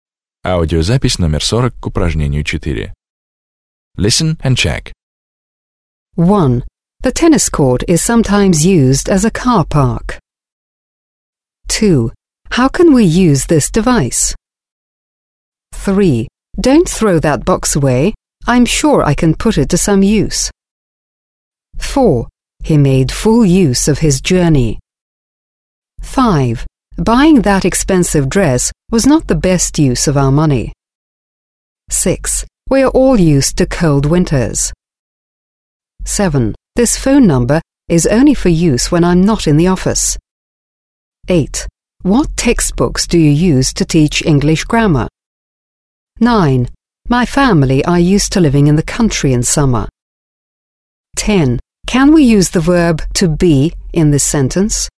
Read the sentences aloud. Mind the way you pronounce use.